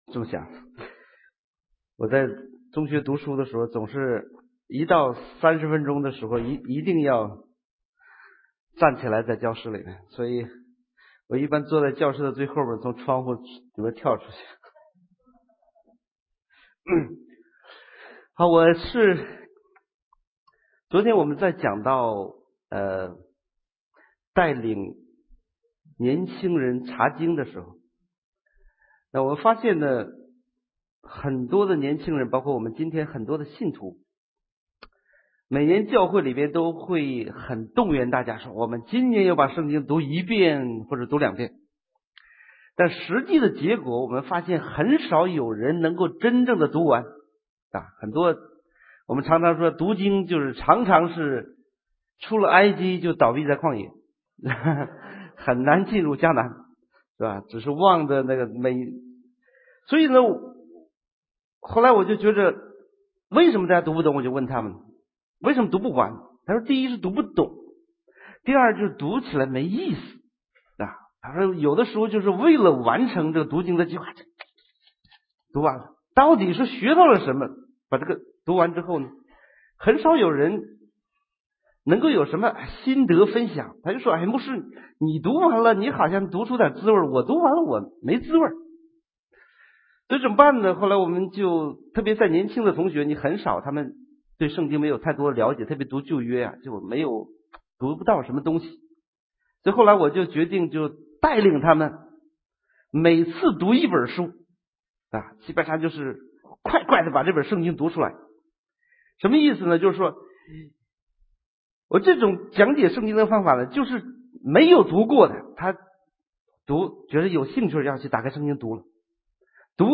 圣经学习--《路得记》